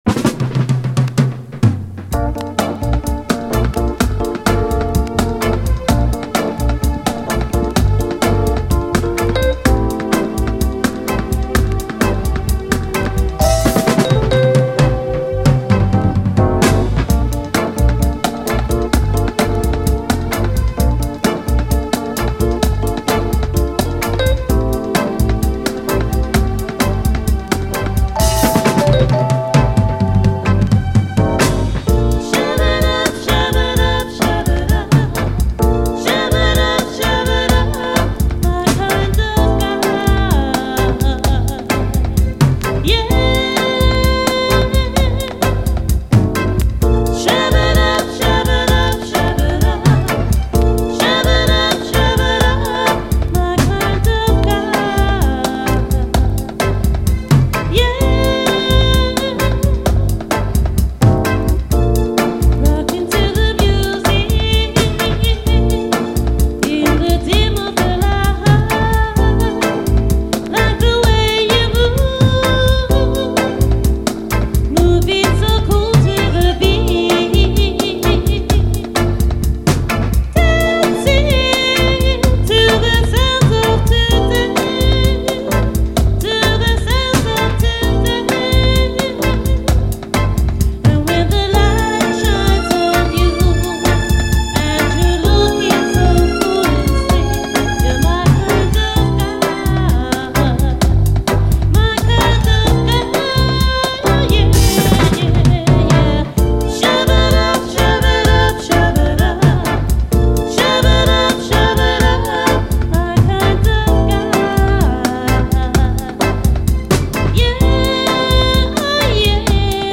REGGAE
幻のようなサウンドが物凄い、レア・キラー・ドリーミーUKラヴァーズ！
ドラムのビートの打ち方がまたカッコいい……。
B面は後半にダブへ接続。